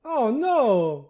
Add voiced sfx
ohno0.ogg